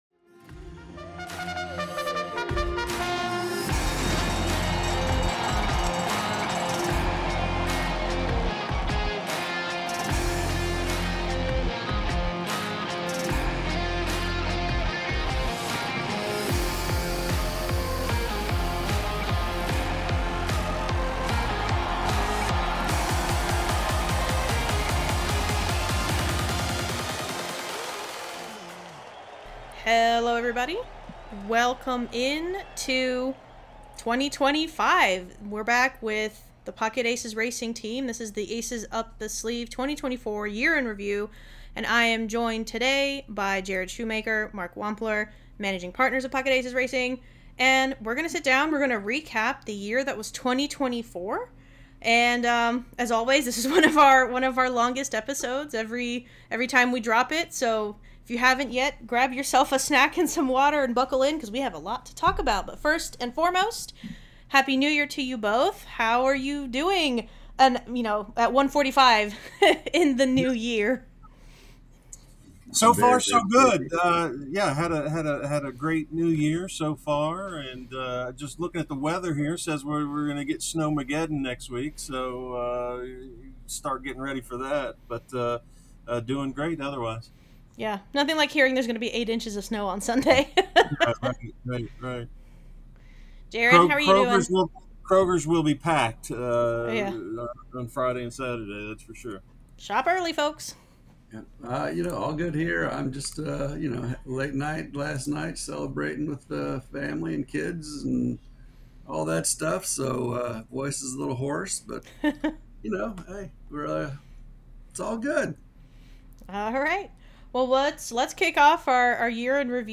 The trio assembles to recap the season that was 2024 for Pocket Aces Racing. A record one for many reasons, this episode is always a marathon anyway, but this time there was a lot to discuss.